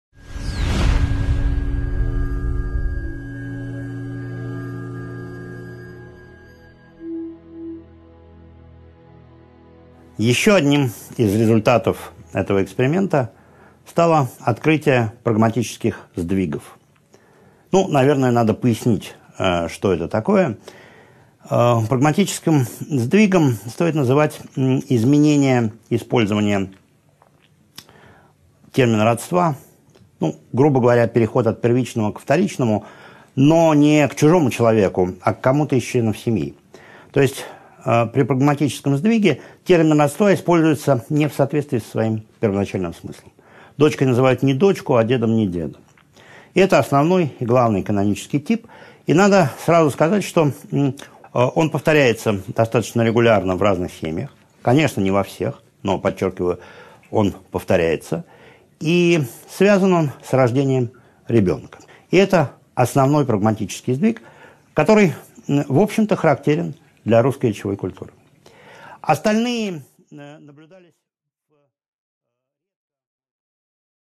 Аудиокнига 7.7 Прагматические сдвиги | Библиотека аудиокниг